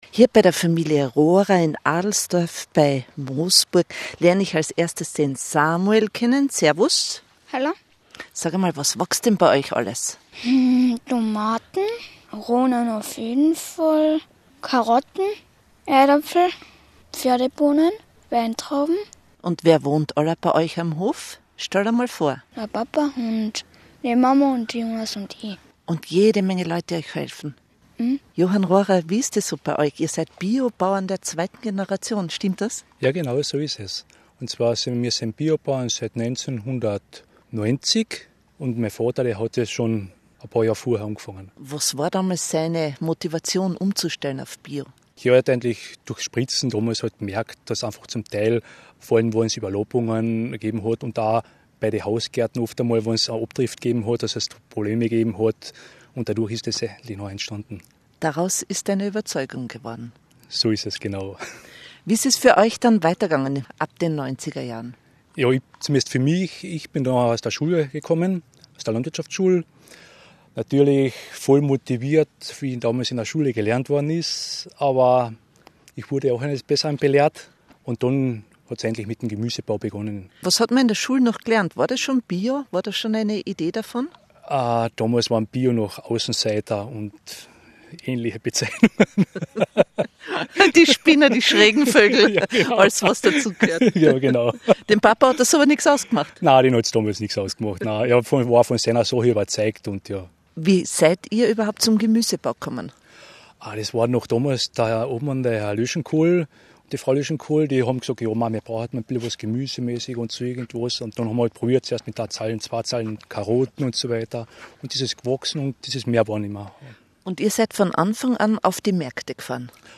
ORF Radio Kärnten Interview